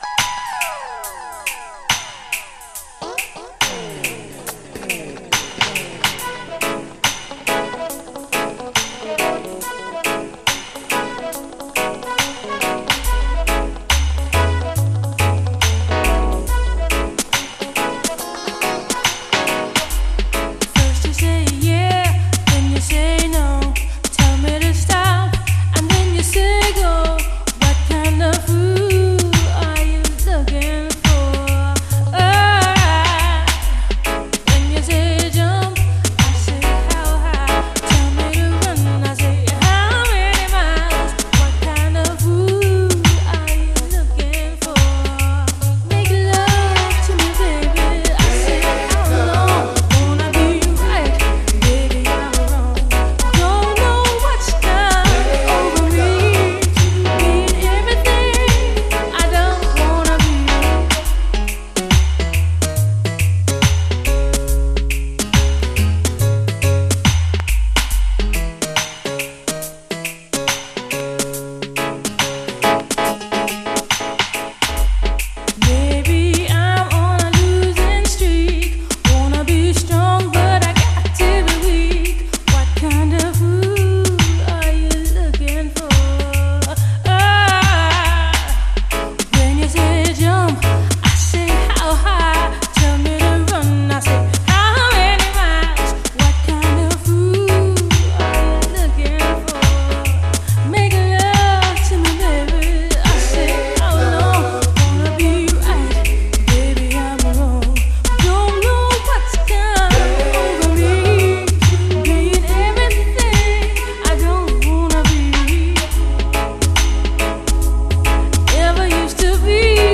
SOUL, DISCO, 70's～ SOUL, REGGAE
やるせないメロディーがダビーなラヴァーズ仕様に！